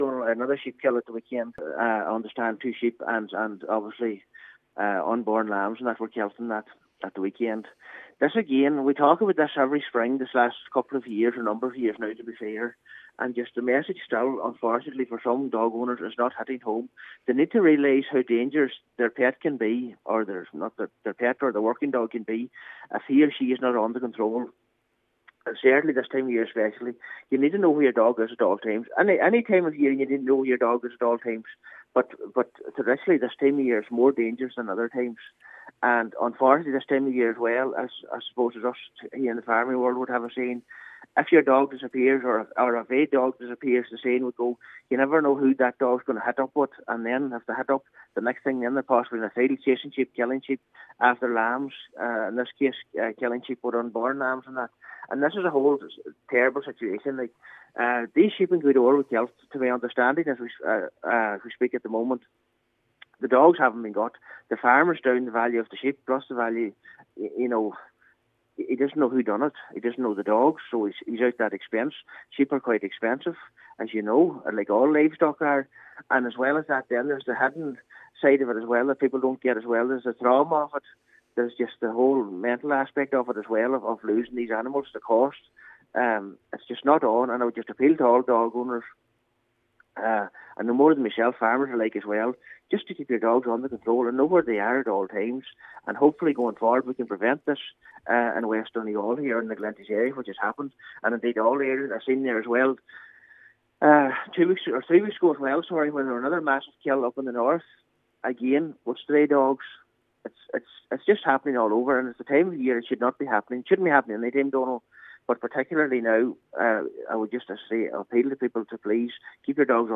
Cllr Michael McClafferty says attacks like these impact badly on farmers…….